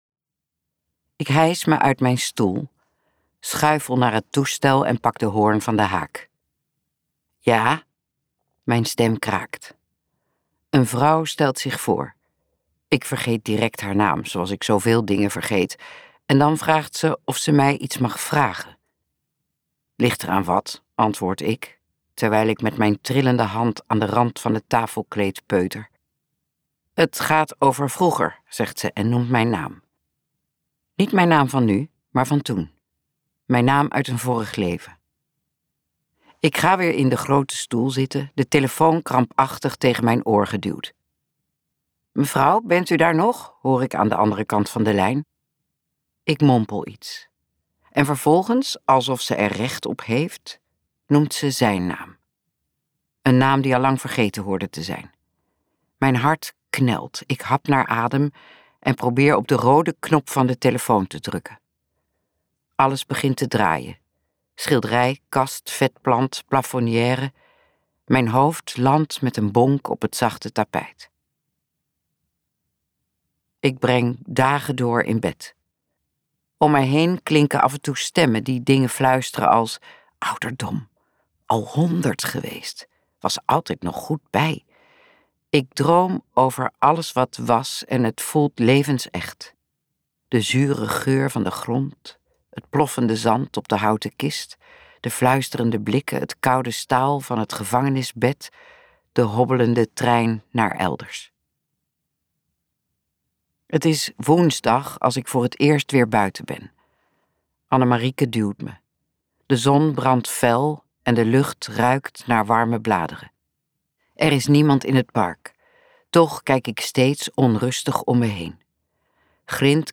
Ambo|Anthos uitgevers - Waar de suikerbieten groeien luisterboek